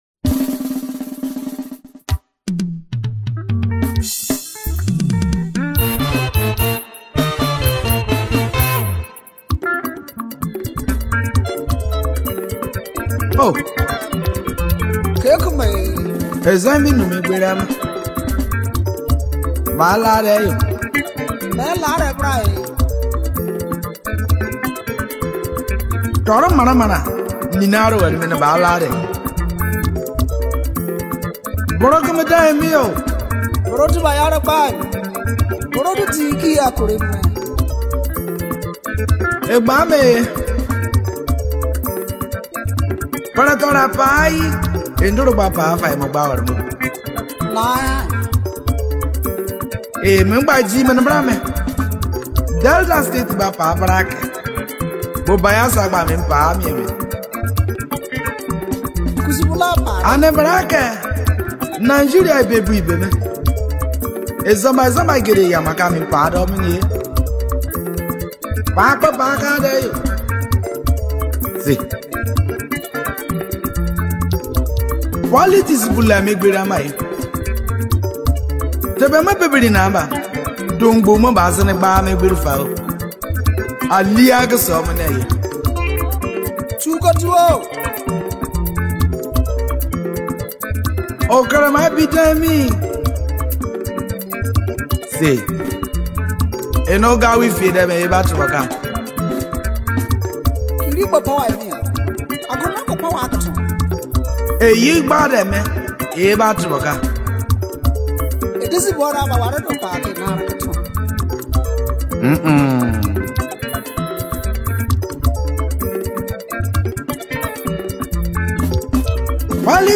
Ijaw music
singer and songwriter